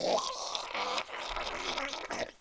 DayZ-Epoch/SQF/dayz_sfx/zombie/idle_17.ogg at dee2e511bd766a0032de9f86b80f159fed73389c